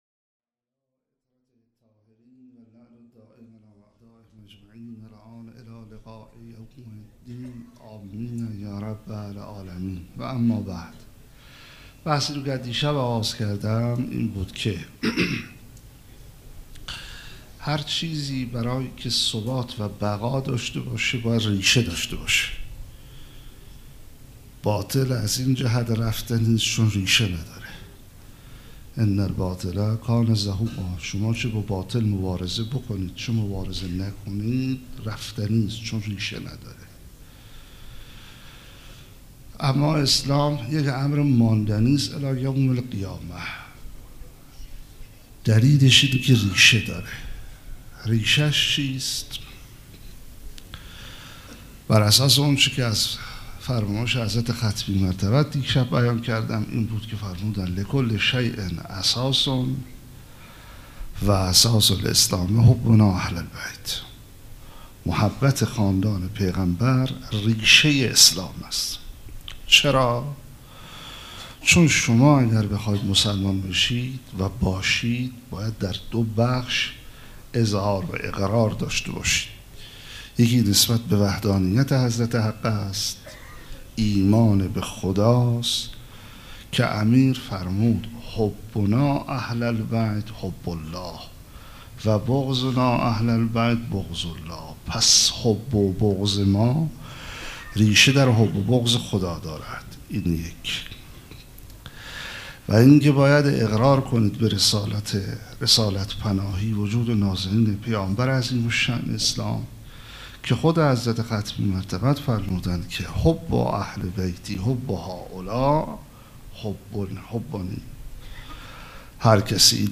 16 بهمن 97 - هیئت ریحانه الحسین - سخنرانی